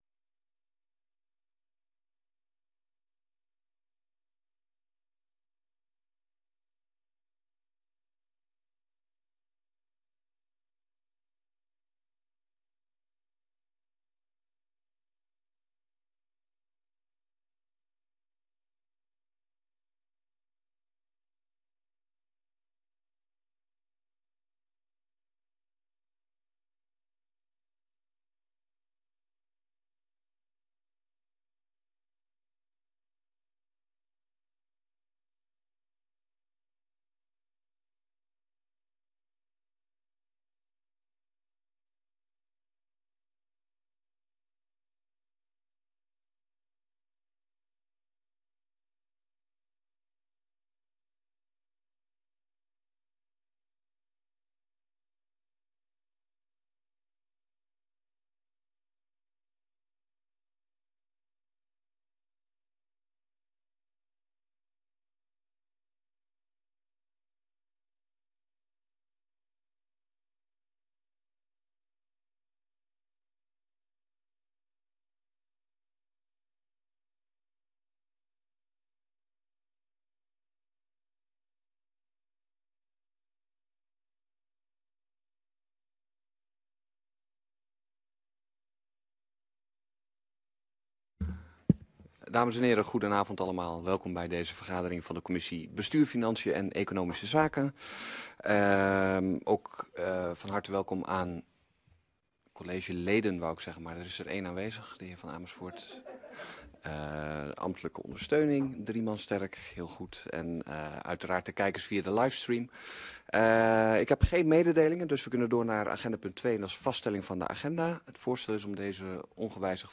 Download de volledige audio van deze vergadering
Vergadering van de commissie Bestuur, Financien en Economische zaken op maandag 12 februari 2024, om 19.30 uur eerst samen met de commissie Woonomgeving en Samenlevingszaken in de raadzaal, daarna fysiek in kamer 63 van het gemeentehuis.